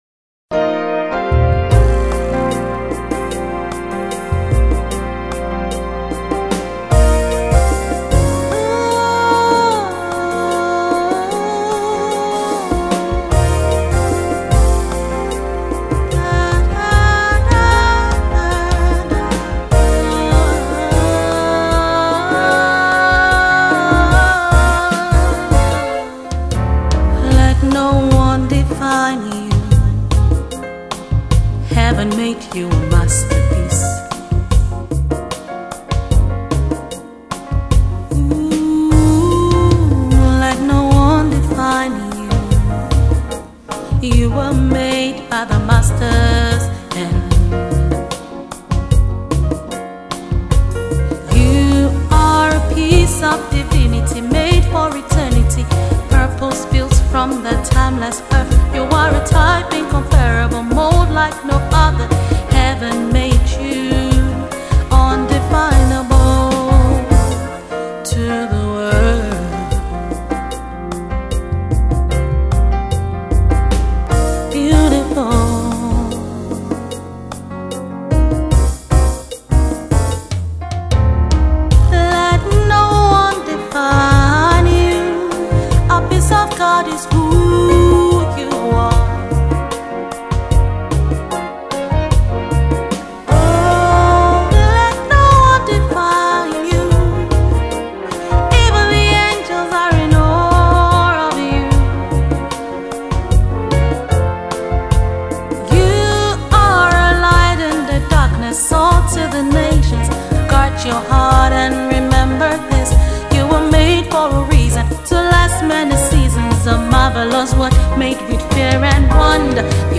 Christian Songwriter/Singer based in Lagos